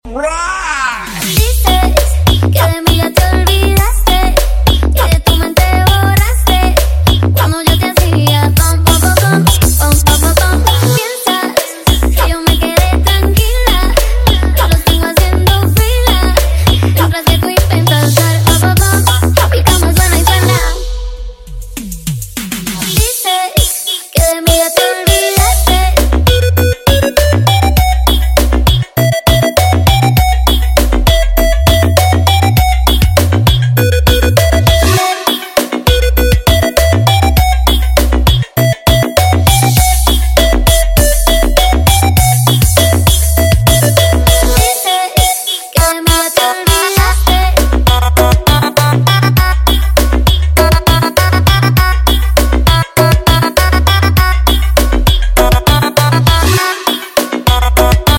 Karnaval Kasin Jogosalam kasin Gondanglegi Malang 2024.